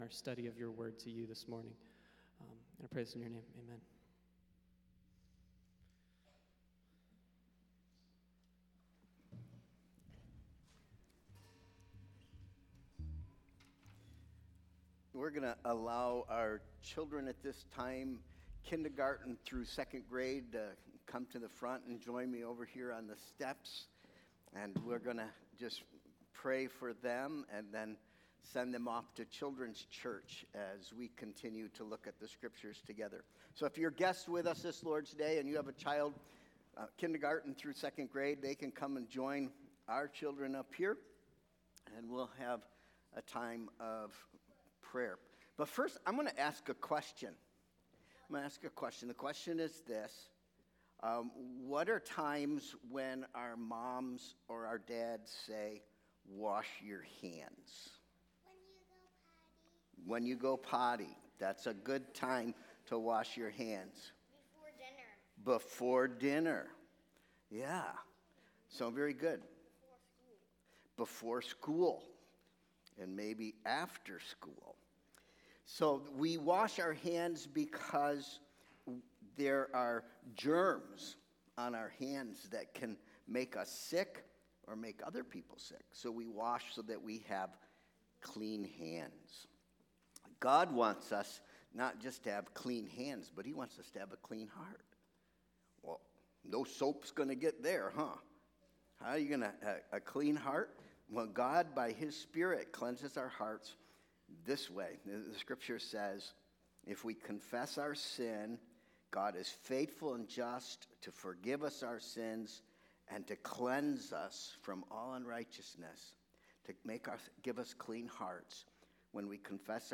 Sermon Proposition: God calls you to cross the finish line of holiness.